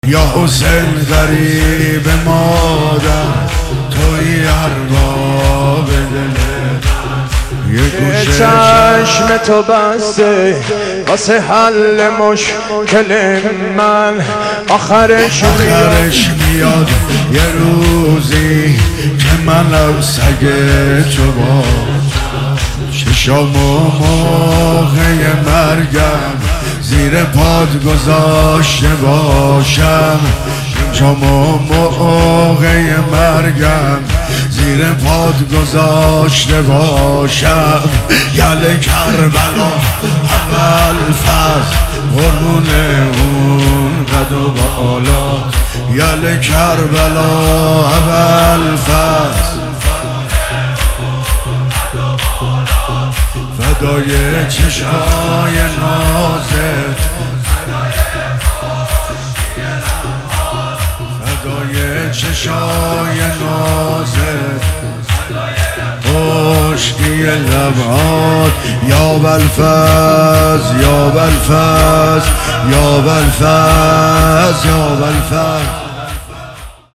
مداحی جدید
حسینیه کربلا تهران